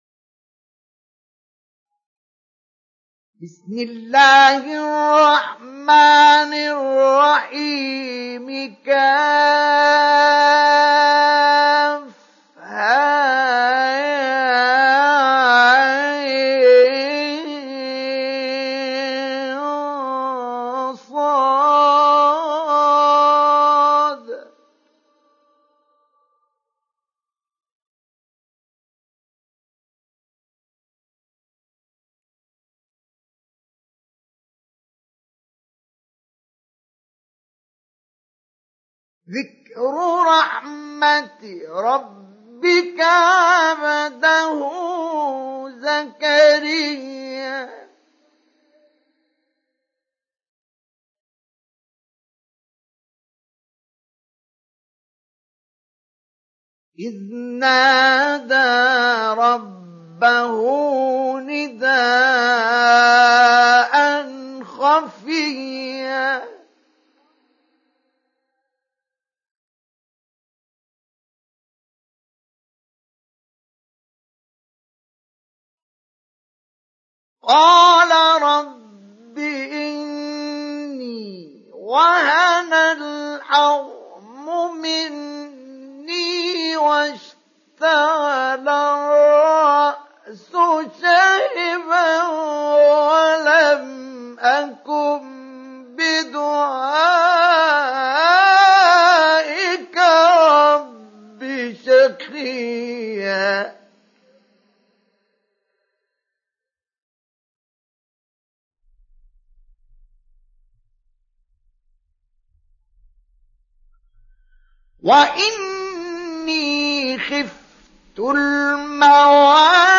سُورَةُ مَرۡيَمَ بصوت الشيخ مصطفى اسماعيل